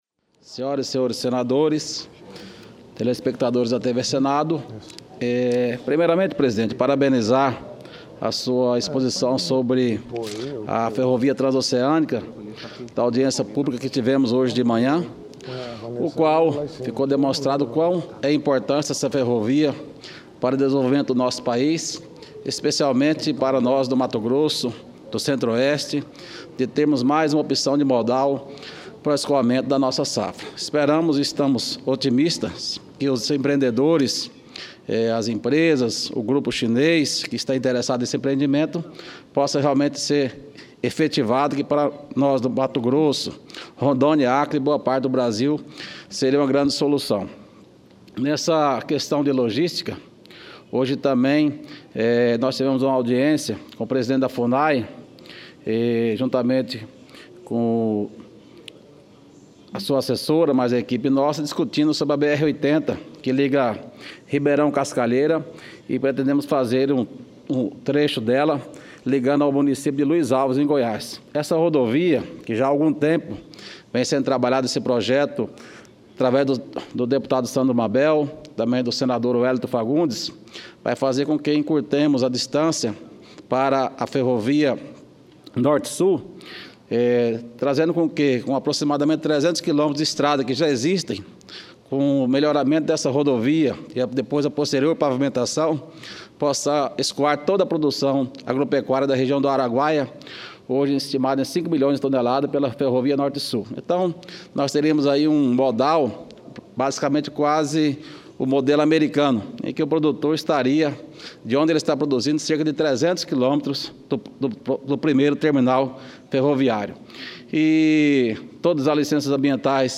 Discursos